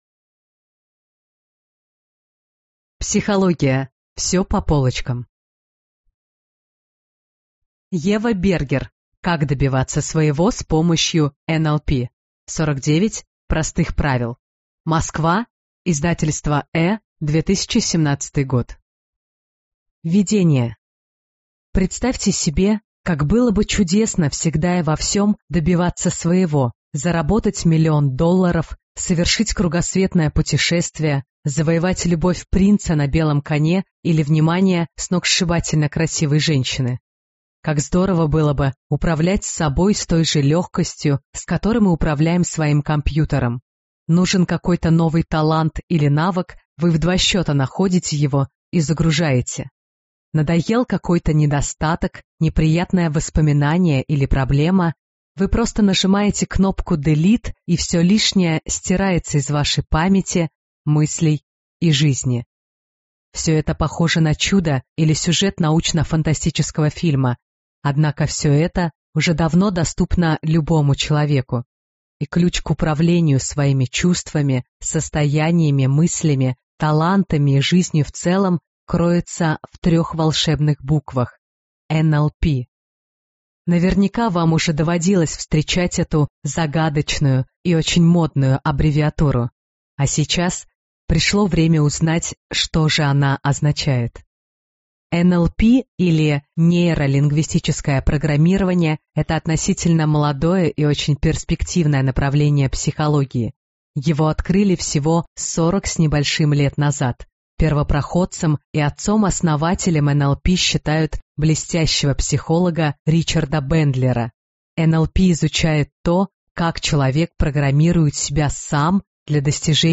Аудиокнига Как добиваться своего с помощью НЛП. 49 простых правил - купить, скачать и слушать онлайн | КнигоПоиск